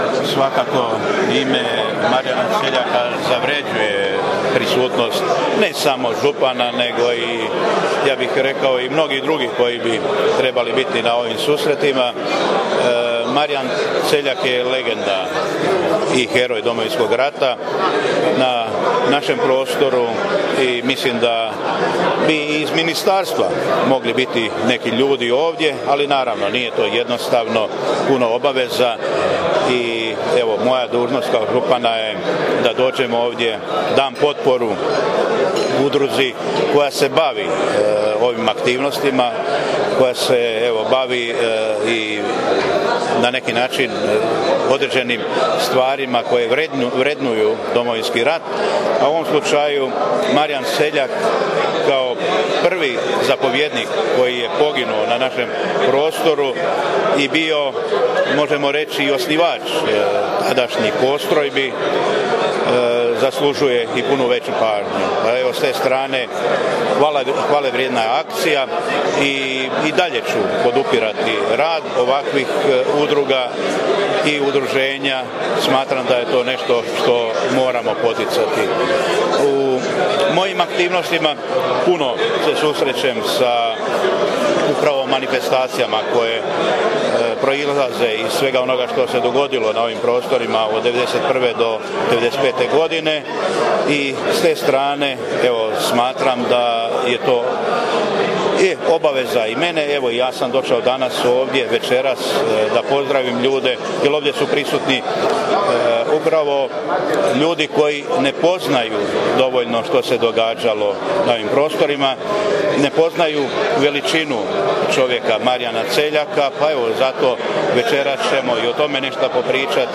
Izjava župana Ive Žinića.